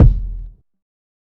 HFMKick3.wav